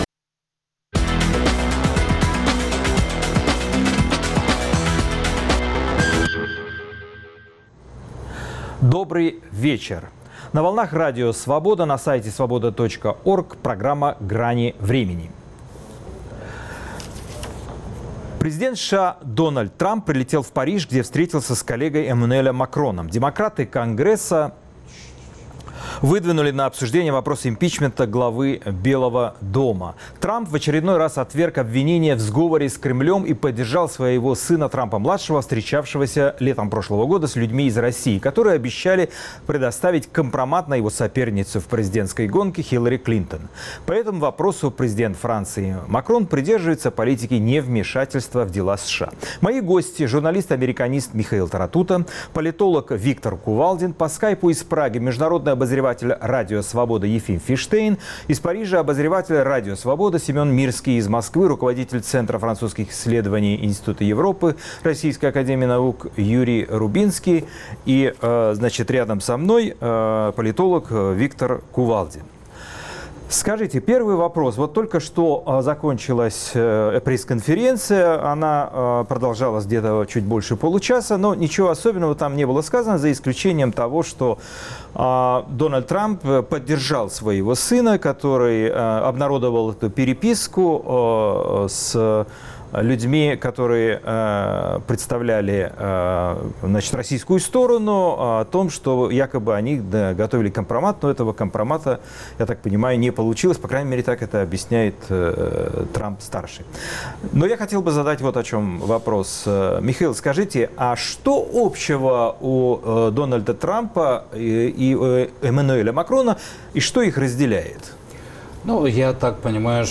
Гости программы: журналист-американист